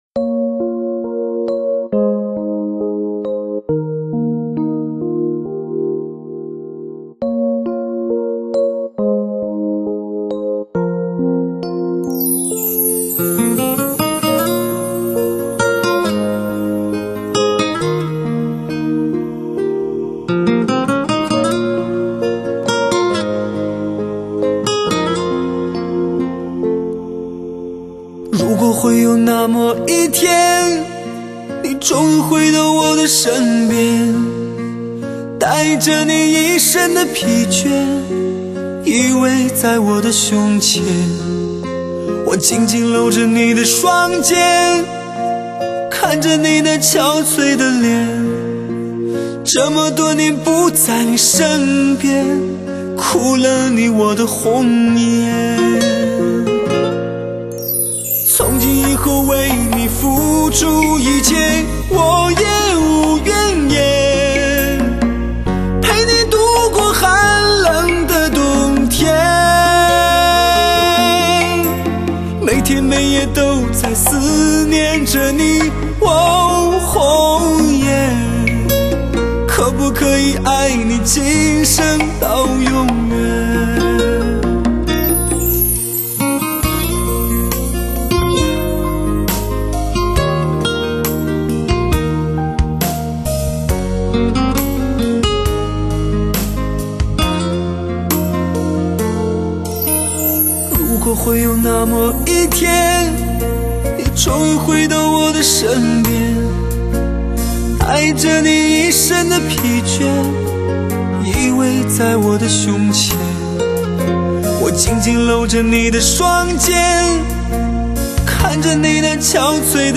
采用最先进的数码音频工作站Pyramix灌录处理，母带直刻。